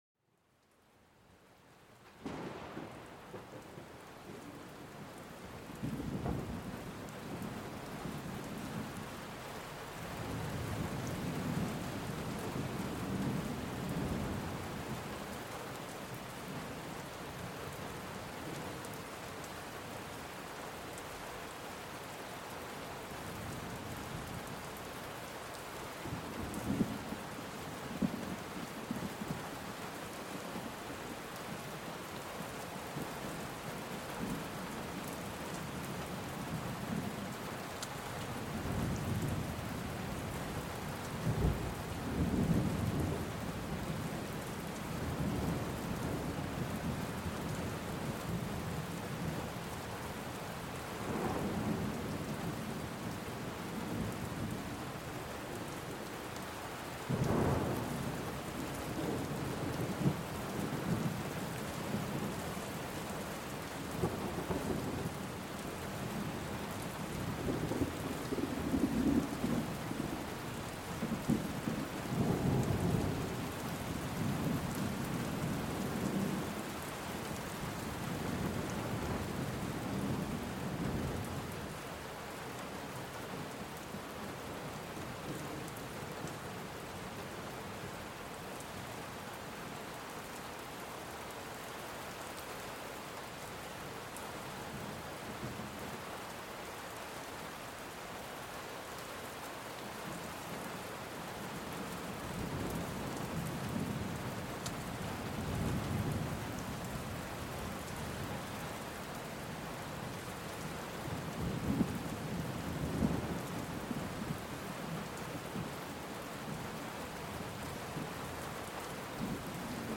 Sumérgete en el corazón de la tormenta y déjate envolver por el majestuoso rugido del trueno.
A través de grabaciones de alta calidad, cada episodio abre una puerta a un mundo de relajación y sueño reparador. Déjate llevar por sonidos puros y armoniosos, desde los susurros de la naturaleza hasta la majestuosidad de los elementos.